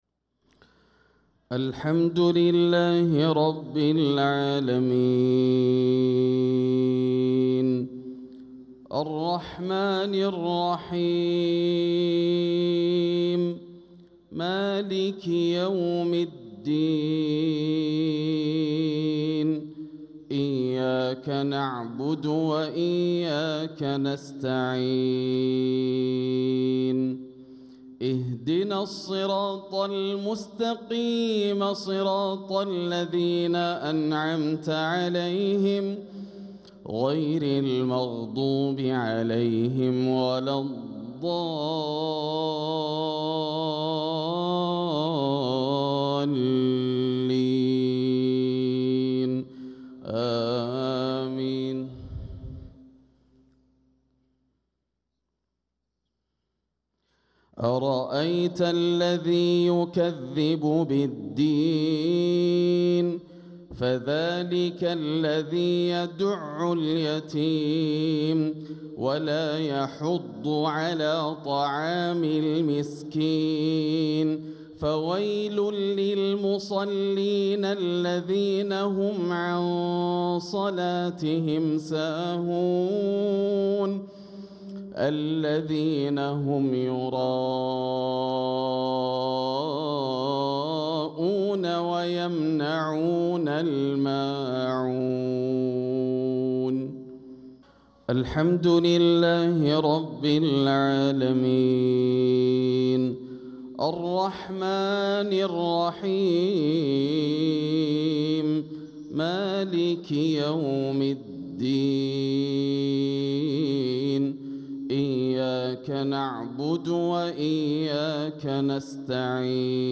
صلاة المغرب للقارئ ياسر الدوسري 23 ربيع الآخر 1446 هـ
تِلَاوَات الْحَرَمَيْن .